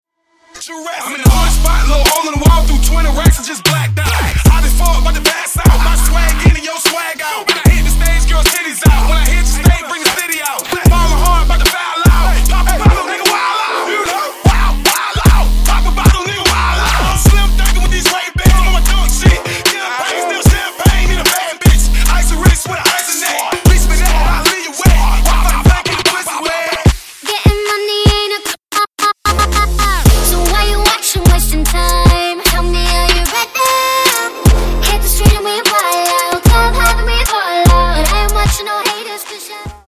• Качество: 192, Stereo
Electronic
Trap
club
Rap
Bass
Hardstyle